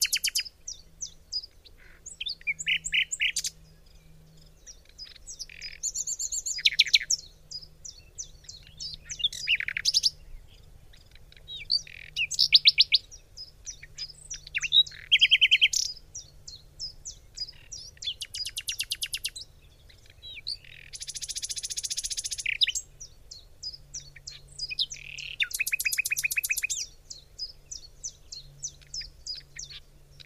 nature_sound_spa_-_charming_birds_in_a_forest
Category 🎵 Relaxation